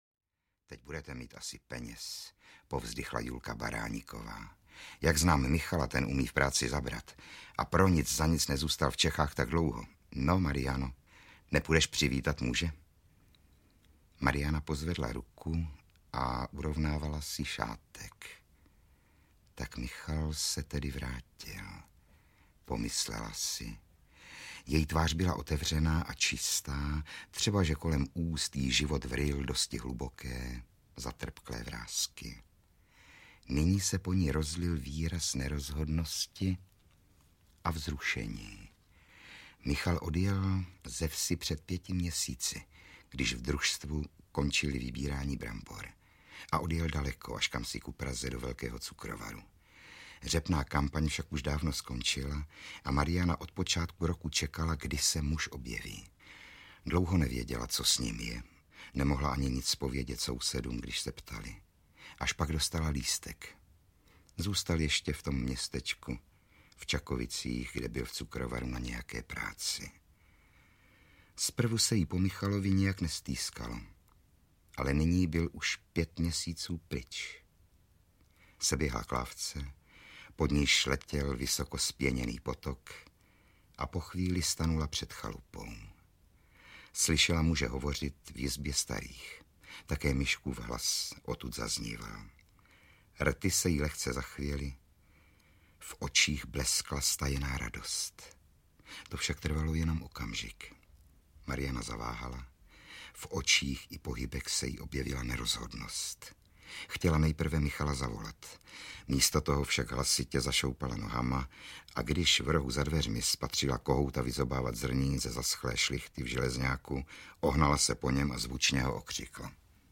Mariana Radvaková audiokniha
Ukázka z knihy
• InterpretRudolf Hrušínský